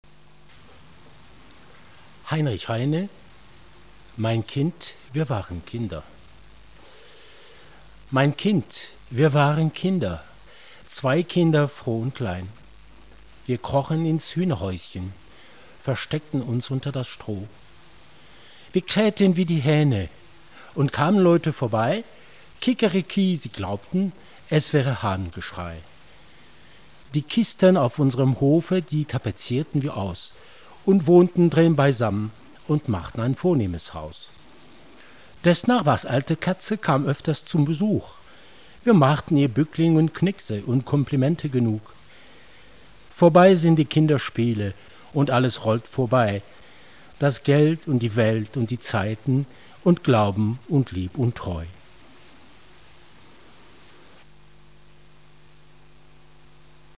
Poésies en langue Allemande Die LORELEI : Diction
Guitare